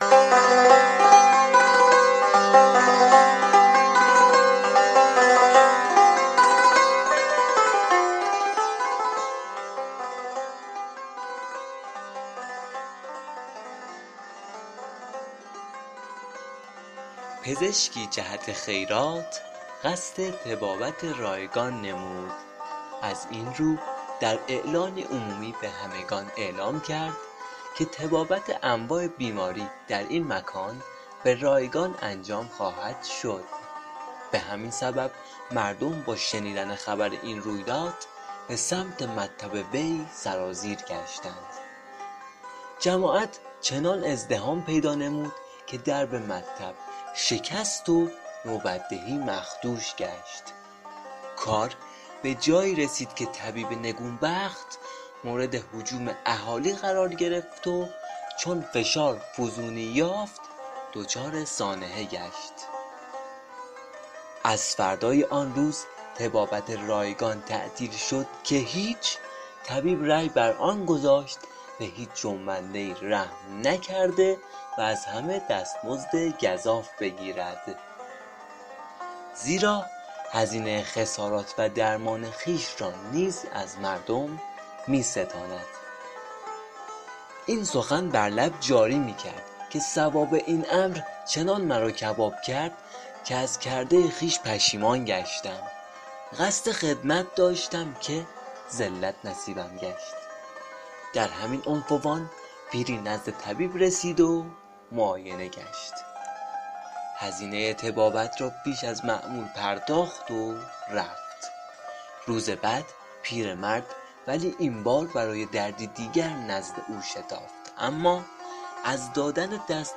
قطعه موسیقی اثر نوازندگی استاد فرامرز پایور